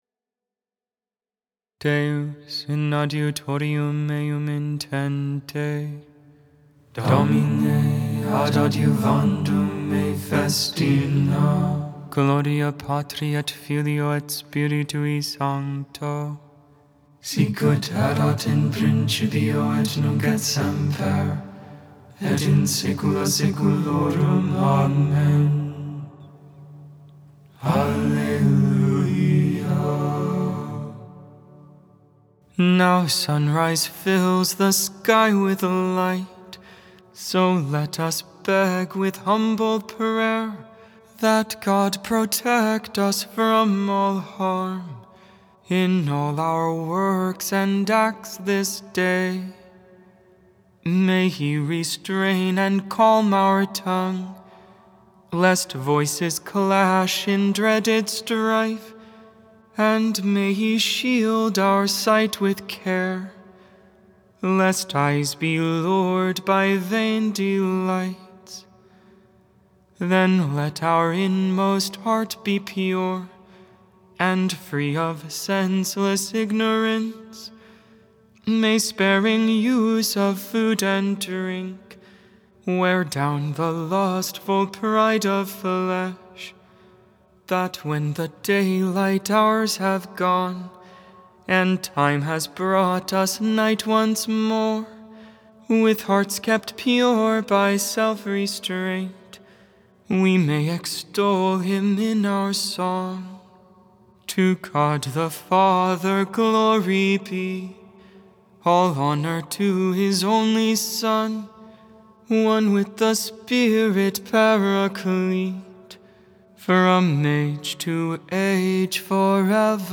Canticle of Zechariah (Tone 4!)